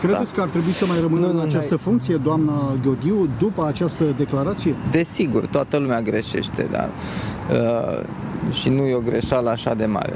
Șeful statului a fost întrebat de jurnaliști în această dimineață dacă Oana Gheorghiu ar trebui să rămână în funcție.